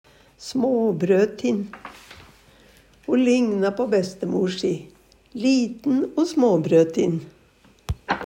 småbrøtin - Numedalsmål (en-US)